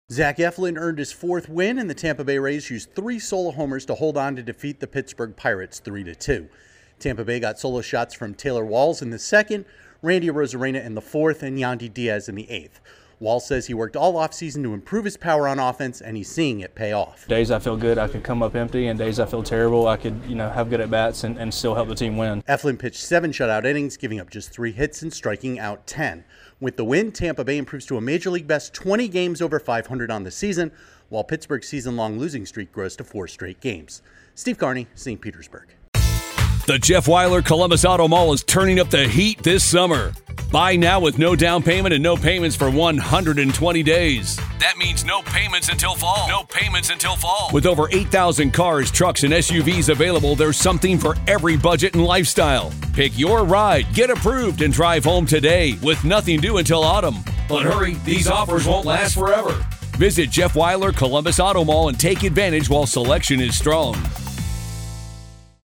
The Rays move a season-best 20 games over .500 by nipping the Pirates. Correspondent